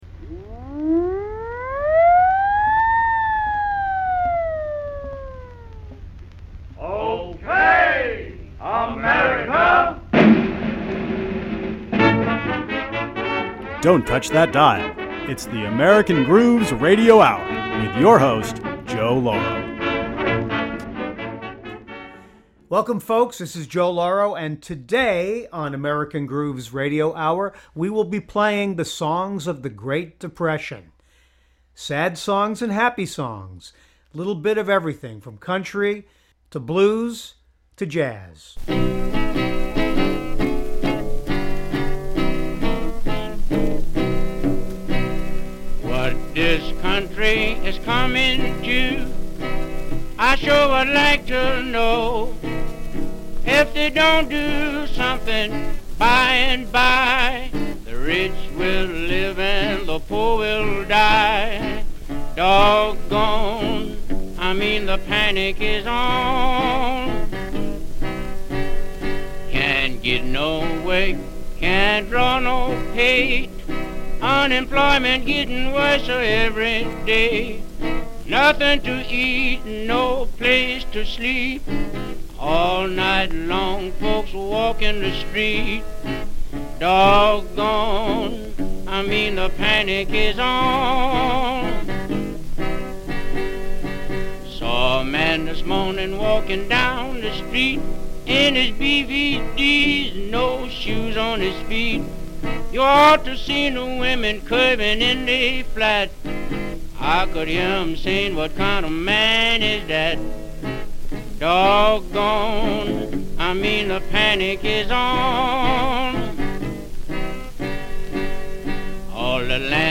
From songs of great despair to songs of hope, the years of the great depression (1929-1937) were rich with topical, blues and tin pan alley songs reflecting the nations worries and optimism. Some forgotten gems from this era will be played and discussed.